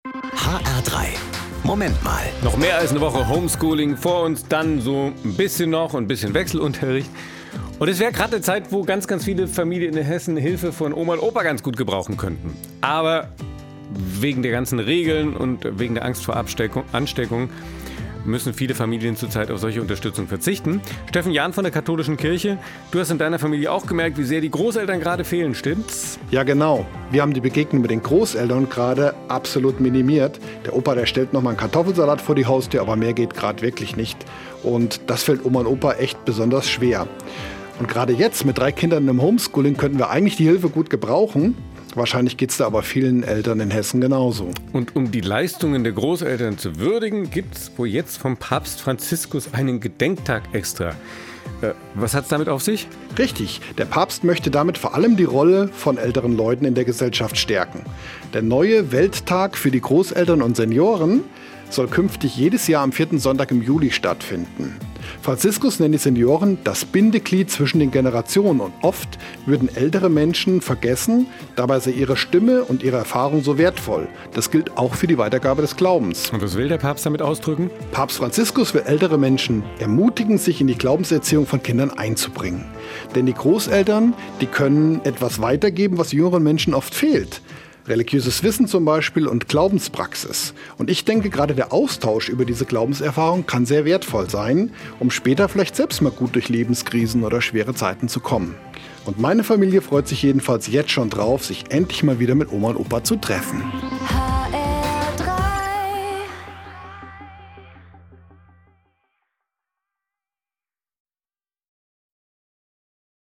Eine Sendung von